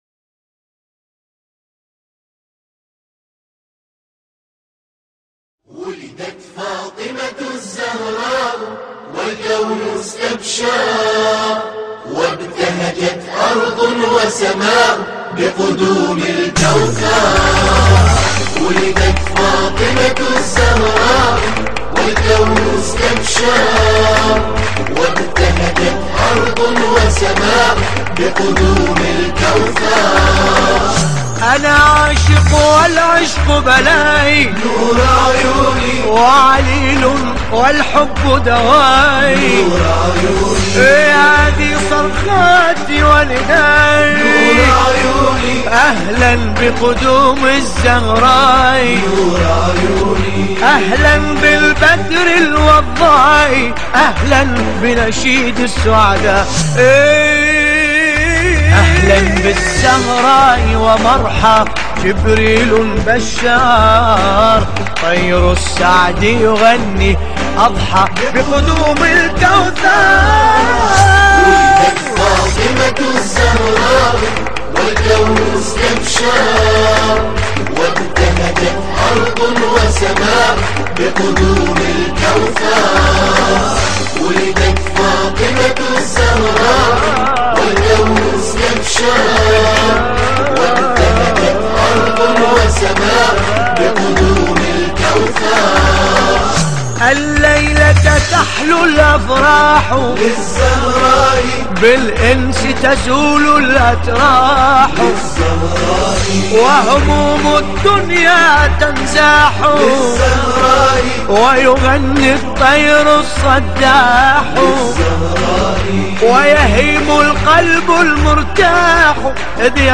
أنشودة رائعة بمناسبة ذكرى مولد سيدة نساء العالمين فاطمة الزهراء عليها السلام أداء الرادود الحاج باسم الكربلائي.
کلیپ مولودی خوانی باسم کربلایی با عنوان «ولدت فاطمه الزهراء» به مناسبت سالروز میلاد با سعادت حضرت فاطمه الزهراء سلام الله علیها